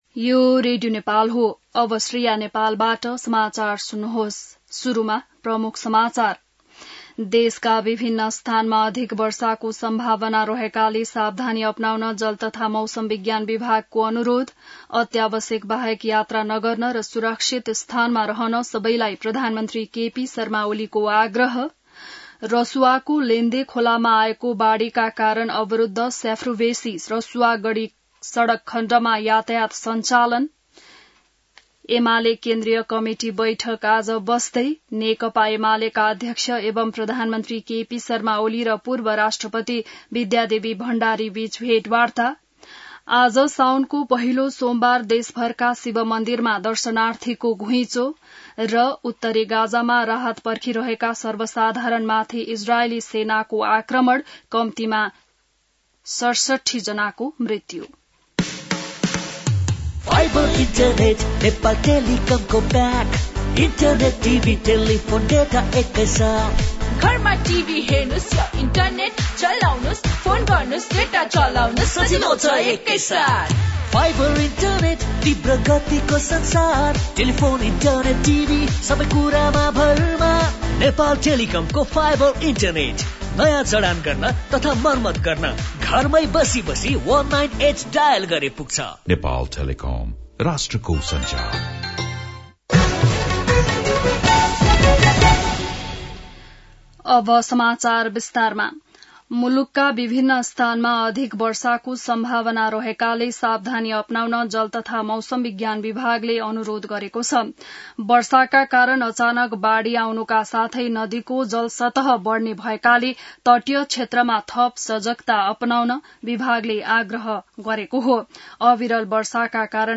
बिहान ७ बजेको नेपाली समाचार : ५ साउन , २०८२